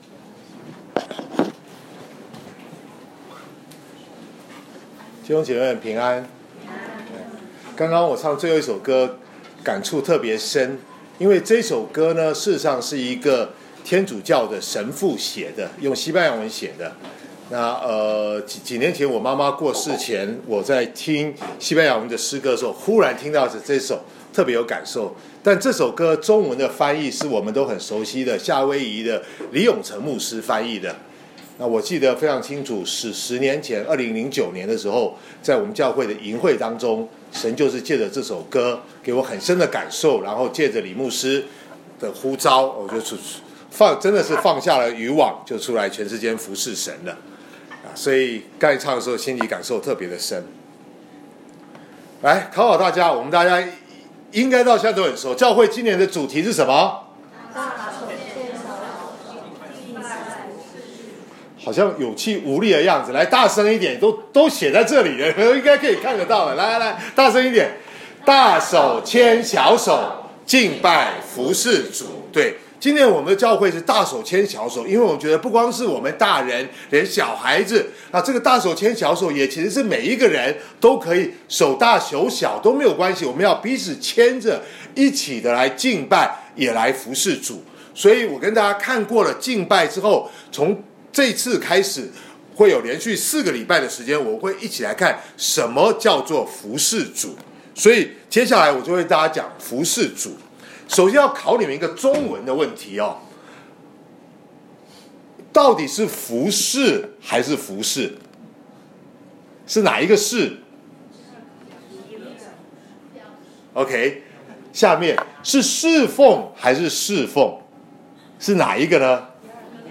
2019年2月10日主日讲道：基督徒的服事观